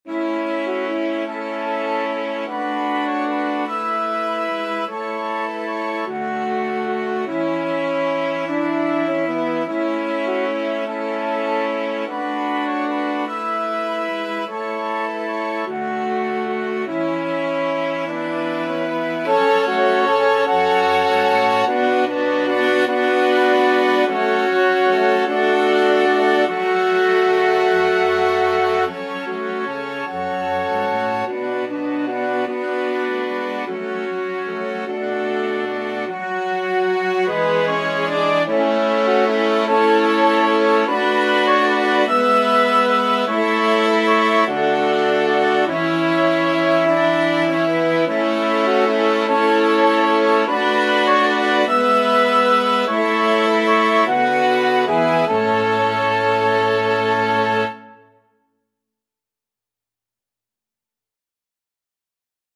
ClarinetFluteViolin
FluteTrumpetViolin
French HornTrumpetViola
Cello
Gently . = c. 50
6/8 (View more 6/8 Music)
Scottish